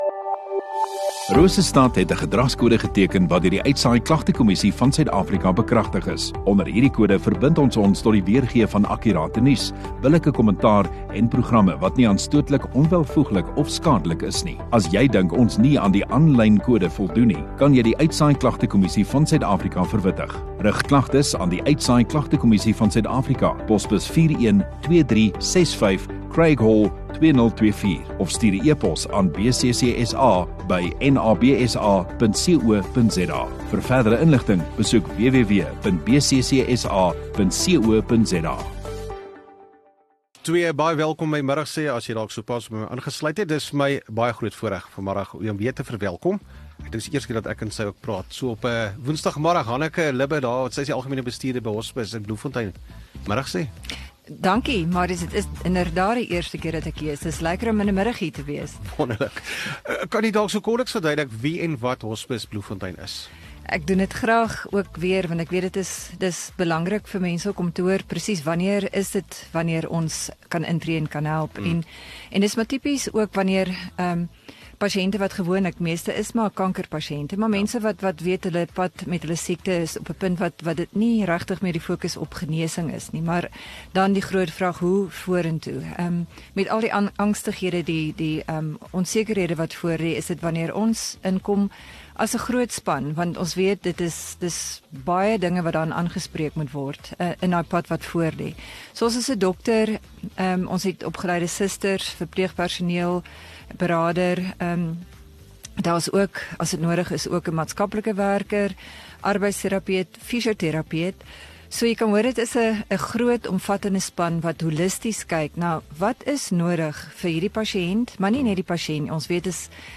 Ateljee-onderhoude met bekende en/of interessante gaste